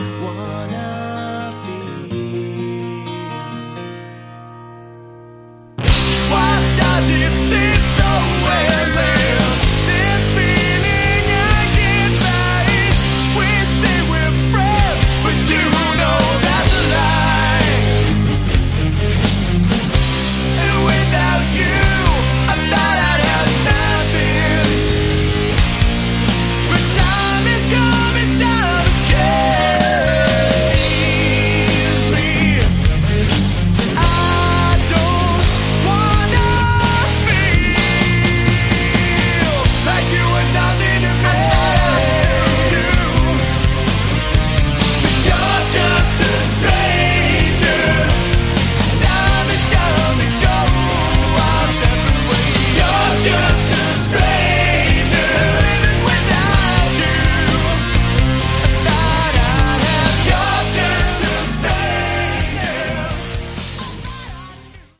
Category: Hard Rock
drums, backing vocals
guitar, backing vocals
lead vocals, acoustic guitar
bass, backing vocals